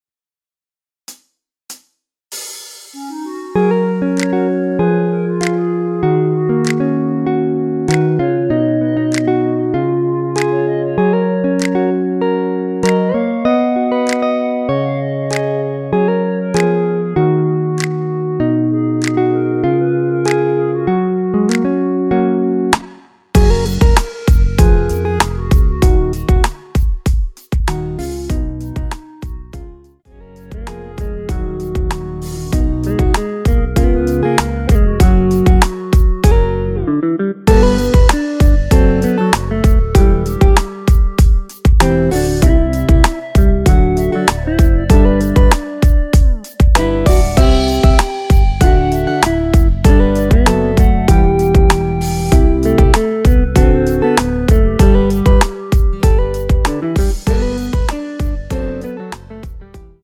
키 F# 가수